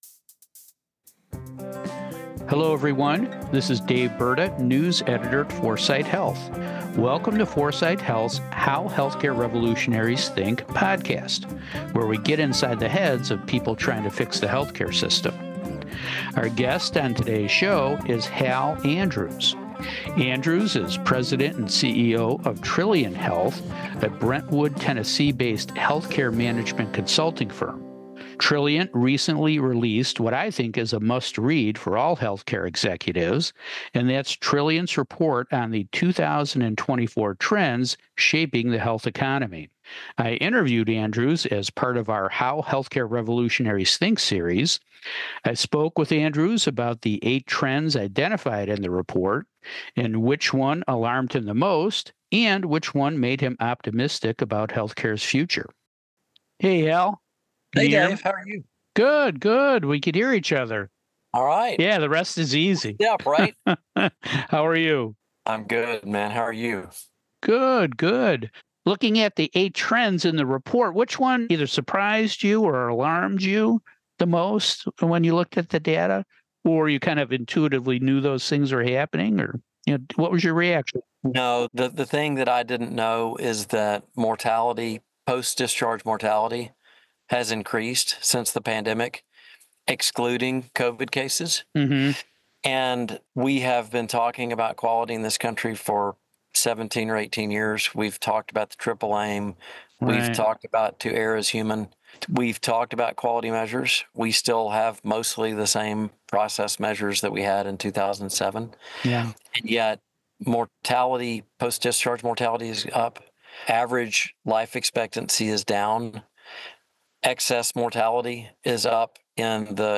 What made him pessimistic? What made him optimistic? Find out in this podcast interview